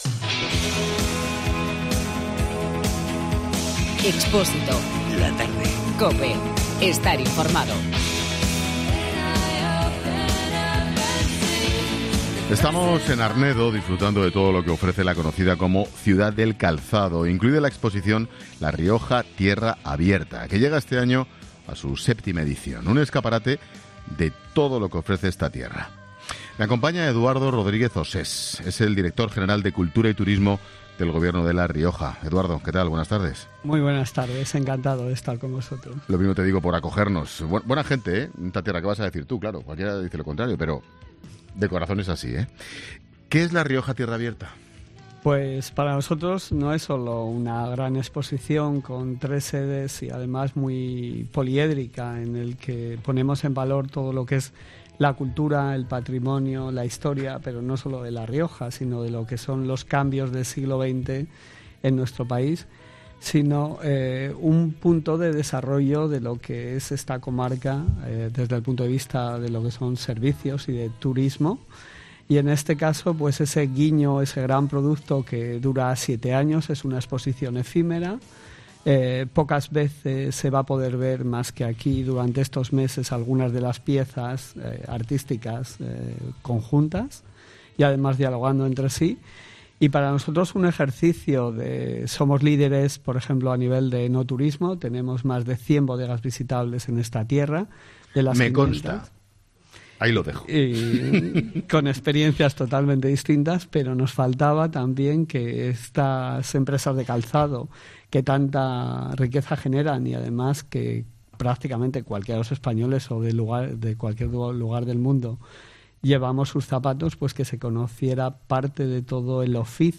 Eduardo Rodríguez Osés, director general de cultura y turismo del Gobierno de La Rioja
Este jueves 'La Tarde' se emite desde la ciudad riojana de Arnedo , más conocida como 'La ciduad del calzado', y por la exposición 'La Rioja Tierra Abierta', que llega a su VII edición con un escaparate de todo lo que ofrece esta tierra.